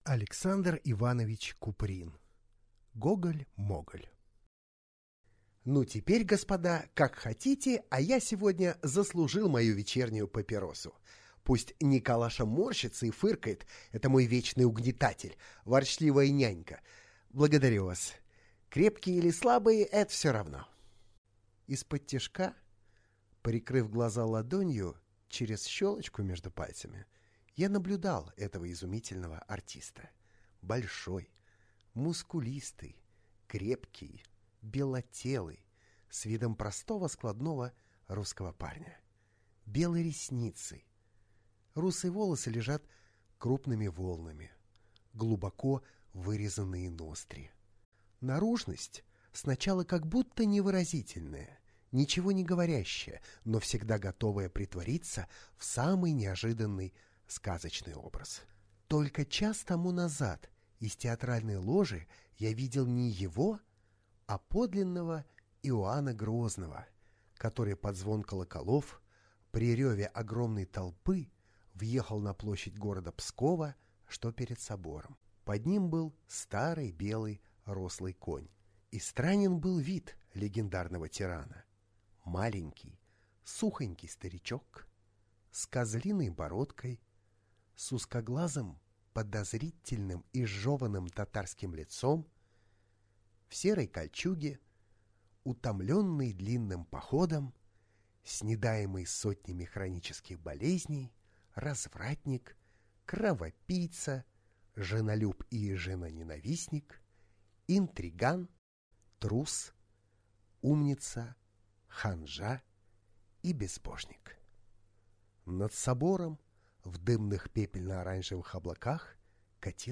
Аудиокнига Гоголь-моголь | Библиотека аудиокниг